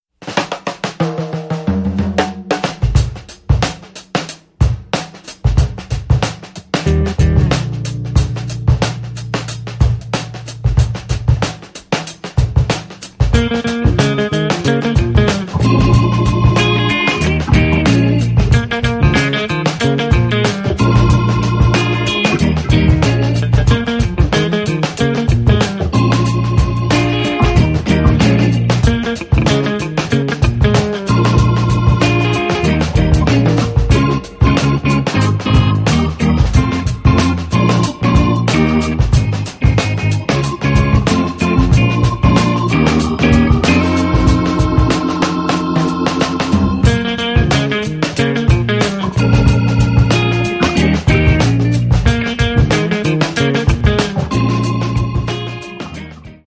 keyboards
guitar
drums
bass